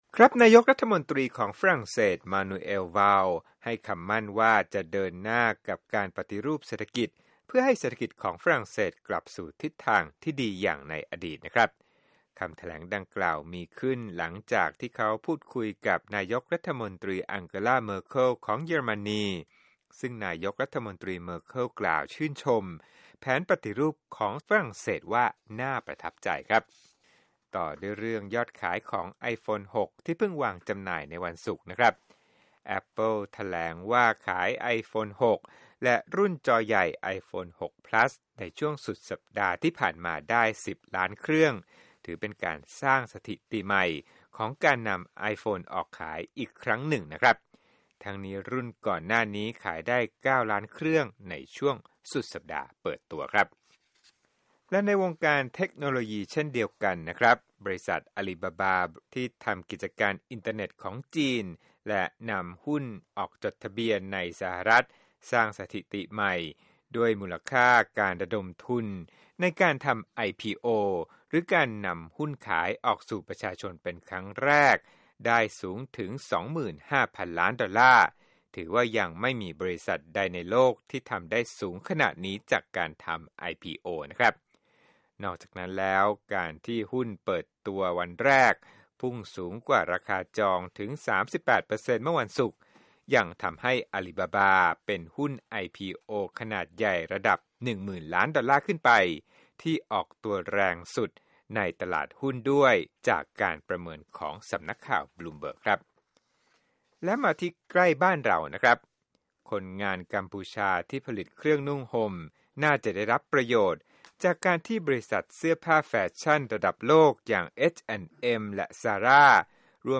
โปรดติดตามรายละเอียดจากคลิปเรื่องนี้ในรายการข่าวสดสายตรงจากวีโอเอ